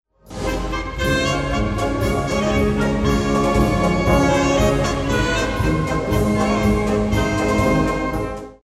Audiobeispiel eines Klarinettensatzes
Audiobeispiel Klarinettensatz
klarinettensatz.mp3